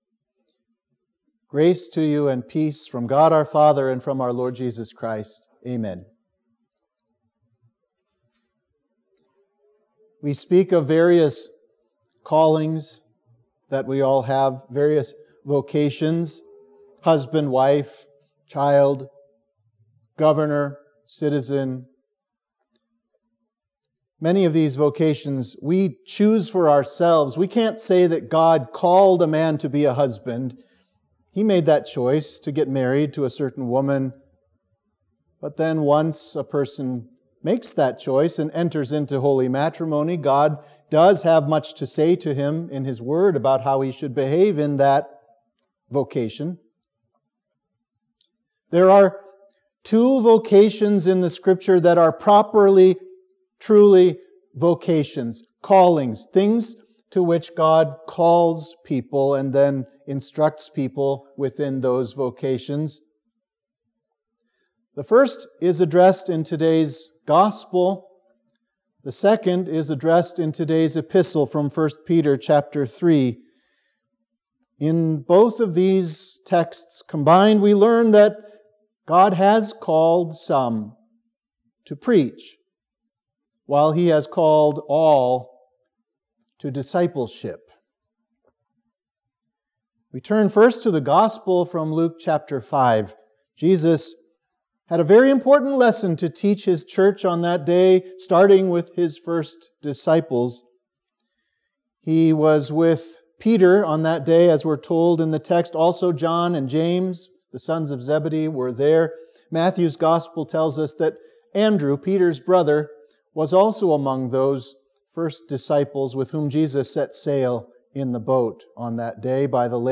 Sermon for Trinity 5